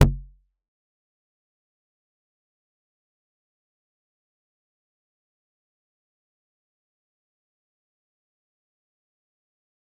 G_Kalimba-B0-f.wav